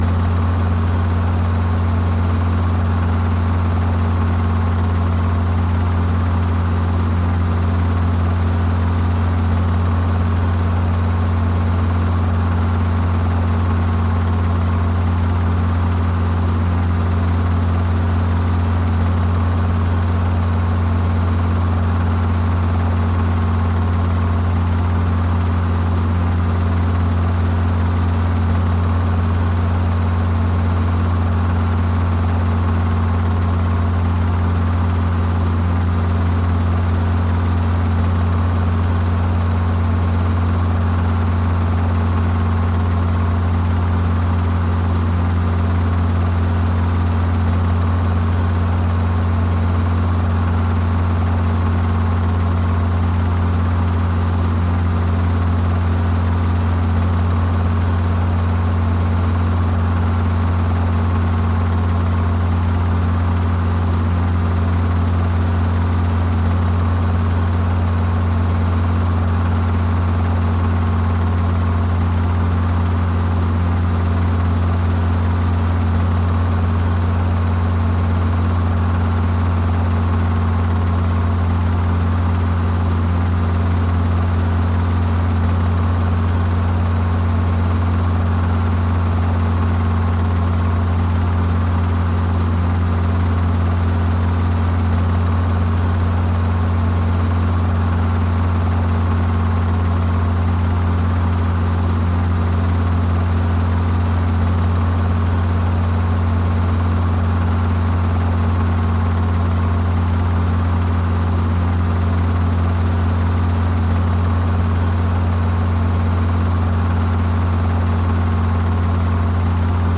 Helicopter.mp3